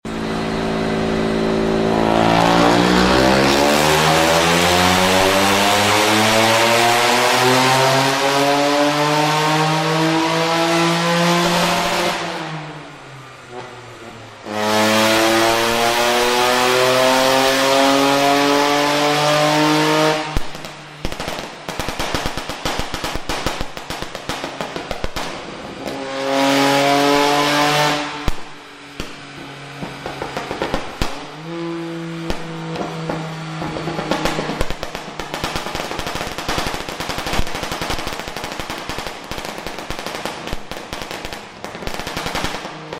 ⚡ Toyota GR Yaris – Dyno Run Pocket Rocket 🔊 1.6L 3-cylinder turbo pushing way above its weight class.